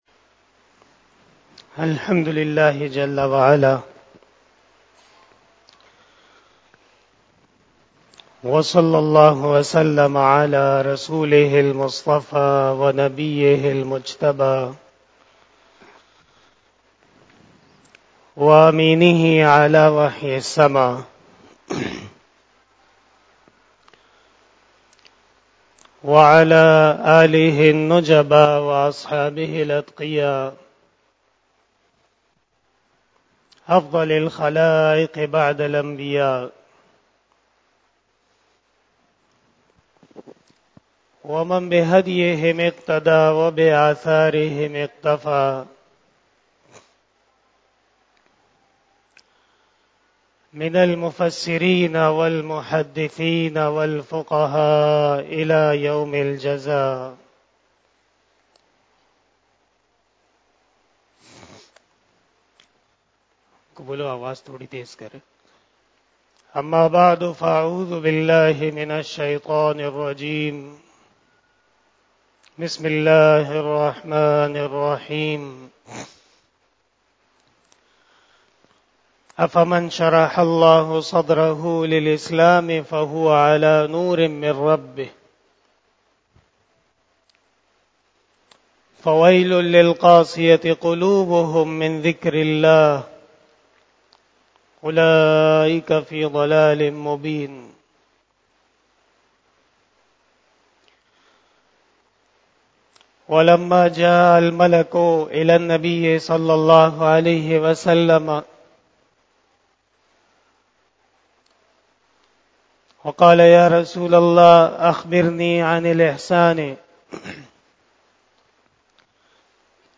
41 BAYAN E JUMA TUL MUBARAK 21 October 2022 (24 Rabi ul Awwal 1444H)
Khitab-e-Jummah